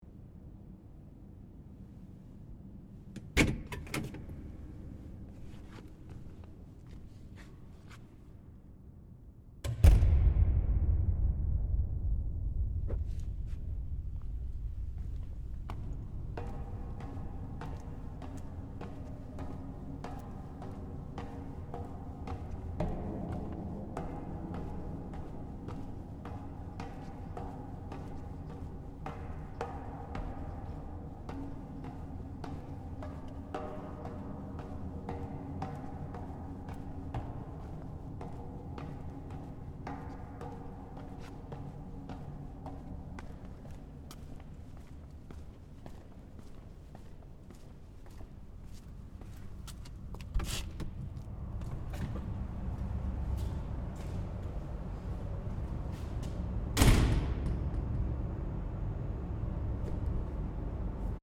Soundscape: La Silla ESO 3.6-metre-telescope downstairs
Walking downstairs from second to ground floor of the ESO 3.6-metre telescope building.
ss-ls-36-downstairs_stereo.mp3